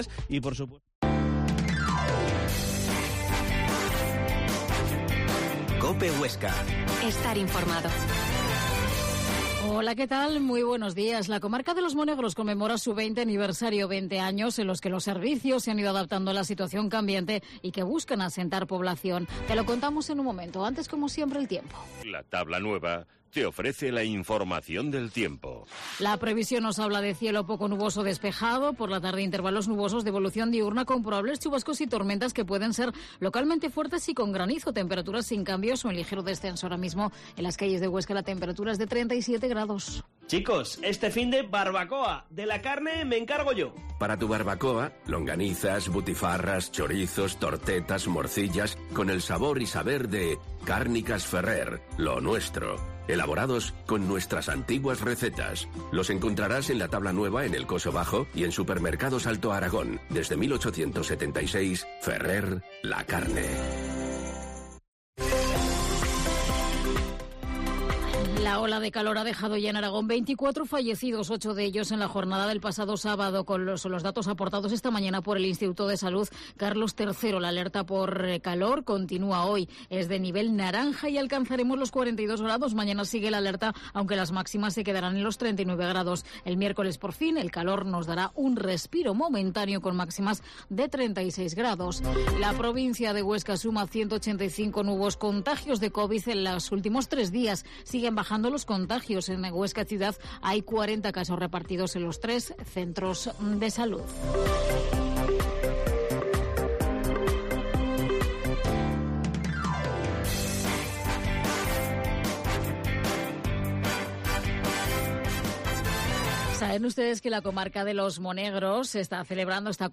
Herrera en COPE Huesca 12.50h Entrevista a Olga Brosed, Vicepresidenta de la Comarca de los Monegros